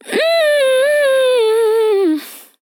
Categories: Vocals Tags: dry, english, female, fill, hige, LOFI VIBES, MMM, sample